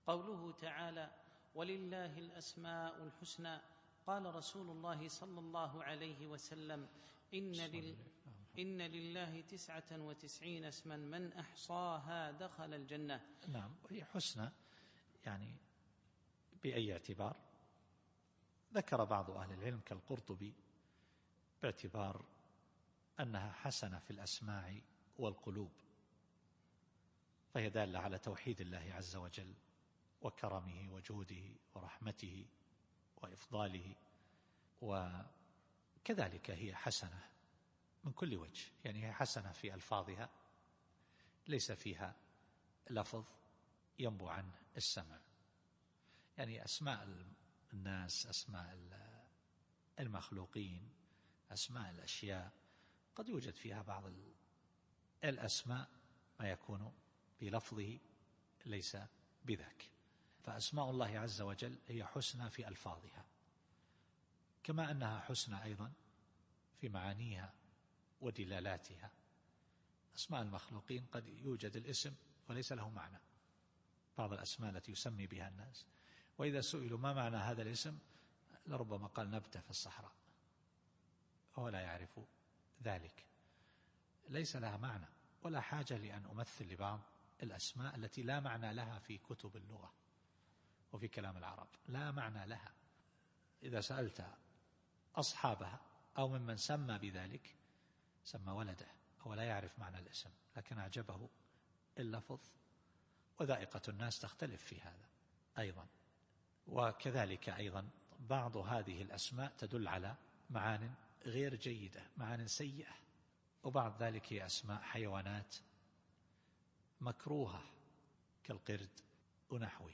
التفسير الصوتي [الأعراف / 180]